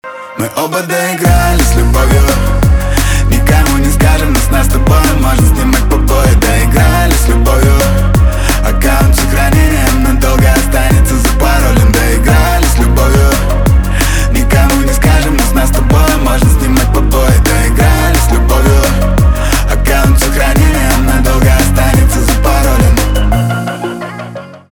русский рэп , битовые , басы , качающие , кайфовые
чувственные